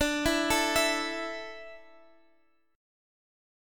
A5/D chord